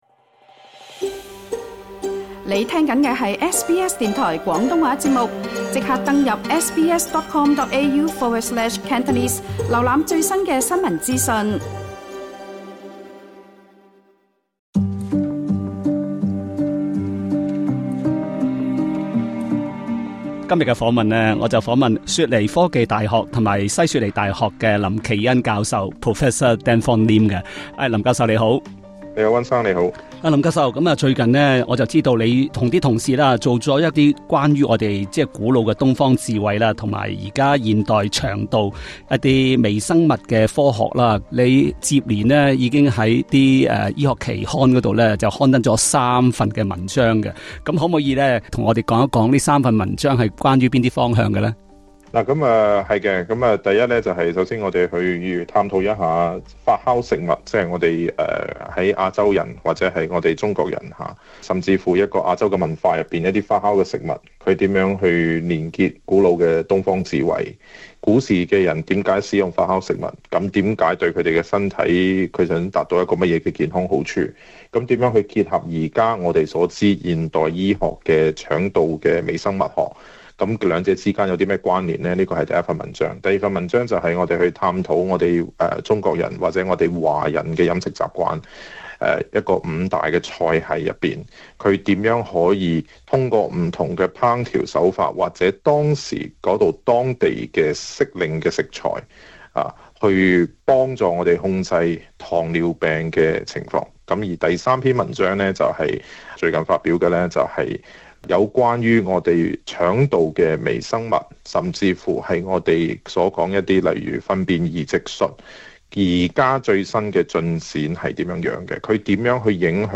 詳情請點擊收聽這節關於發酵食物與腸道健康的專訪。